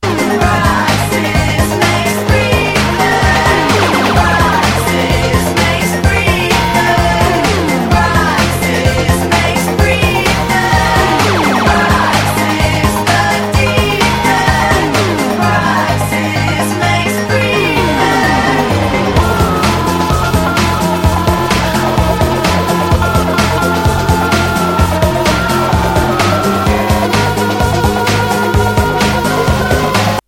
Electro / Krautrock / Alternative Synthpop Lp
Electro / Electronic Indie / Alternative Synthpop